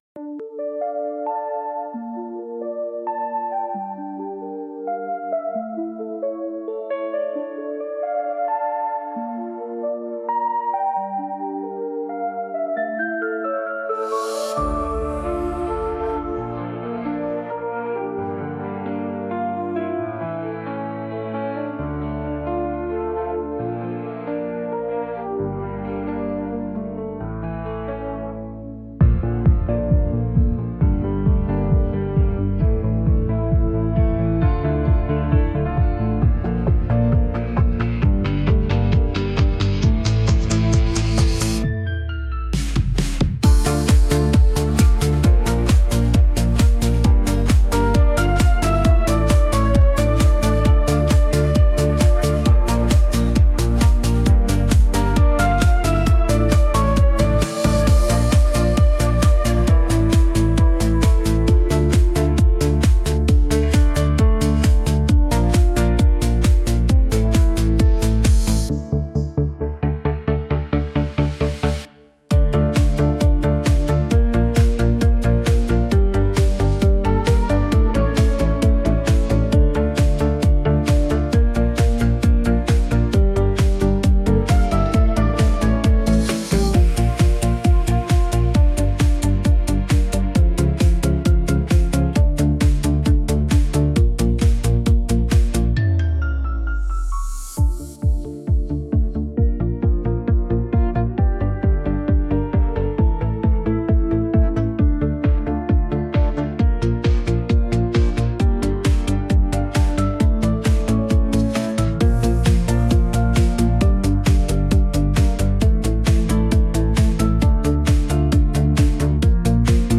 Movie, Romantic, Instrumental | 04.04.2025 17:31